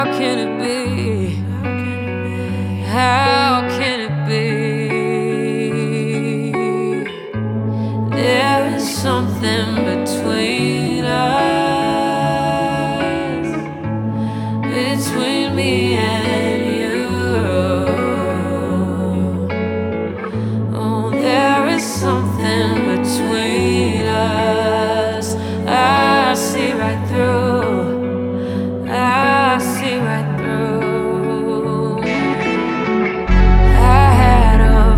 Жанр: Рок / R&B / Соул